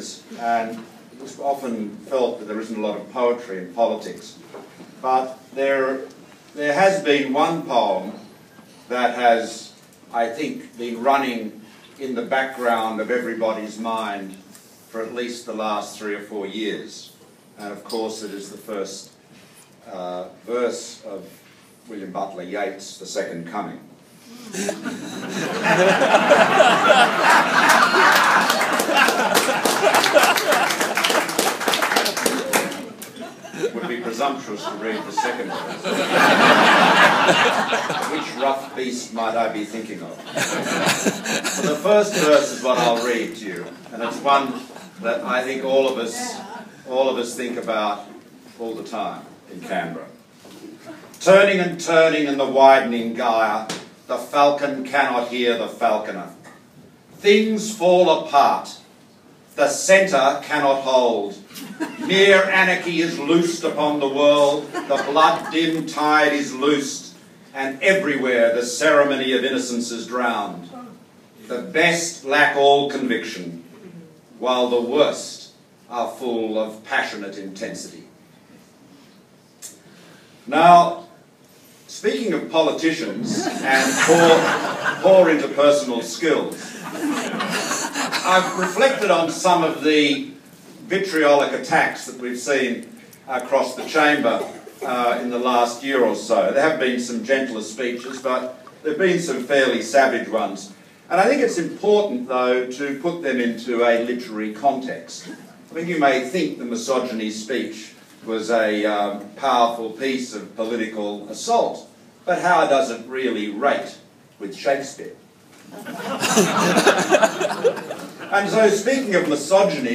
Here’s the proof:  recorded at his recent appearance at the Pig’s Arms  Poets at the Pigs…